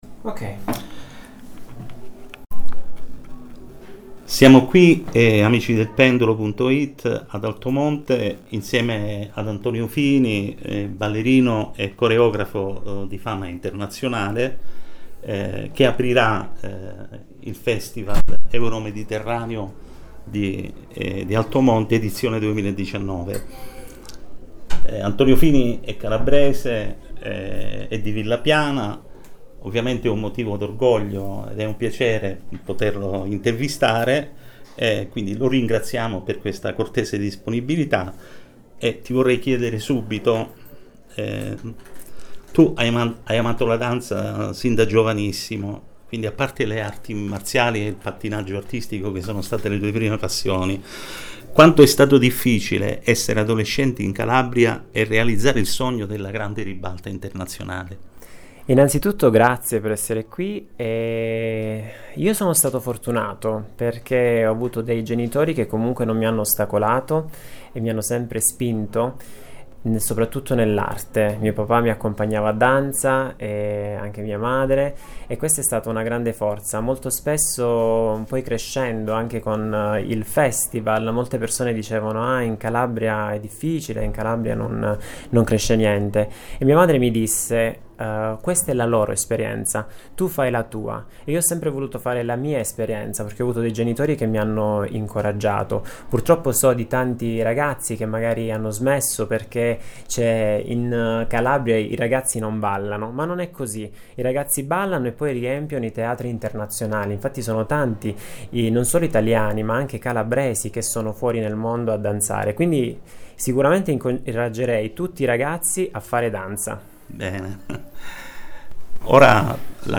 Lo ringraziamo per essersi concesso ai nostri microfoni.